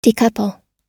Transcription and pronunciation of the word "decouple" in British and American variants.